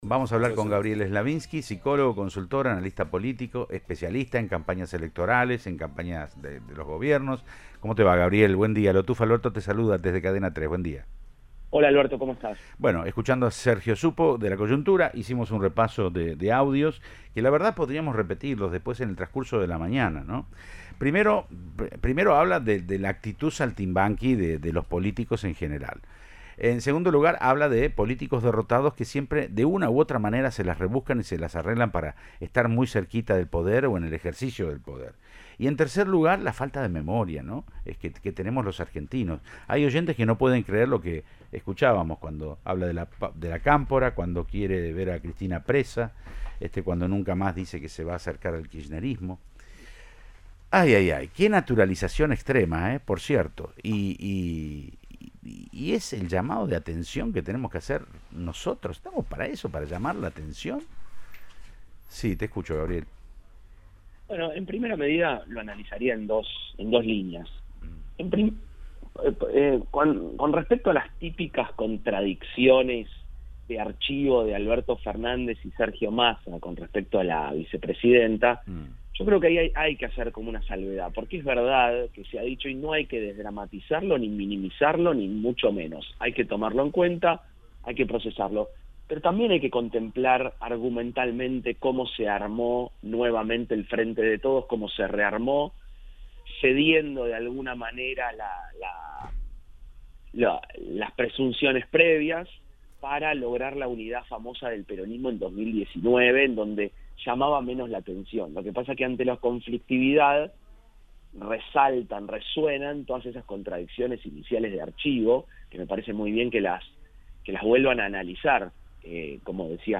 En diálogo con Siempre Juntos de Cadena 3 Rosario para el analista político “el gobierno pasa por una pasividad presidencial en la que se ve a Alberto Fernández pasivo en cada intervención en una posición de debilidad importante”.